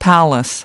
5 palace (n) /ˈpæləs/ Cung điện